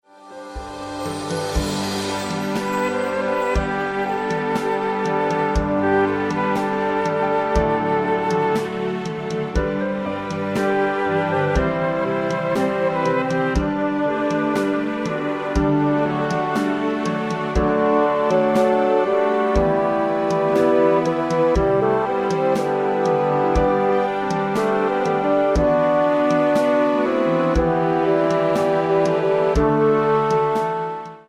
Instrumentals